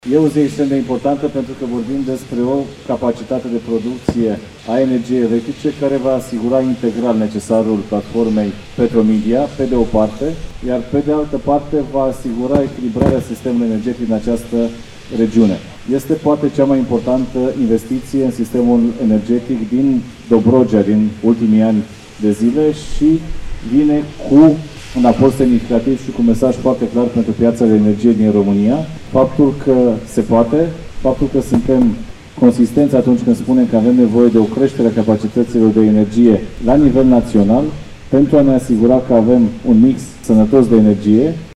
Prezent la rafinărie, ministrul Energiei, Bogdan Ivan, a spus că este cea mai importantă investiție din Dobrogea din ultimii ani: